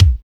UGH  KICK.wav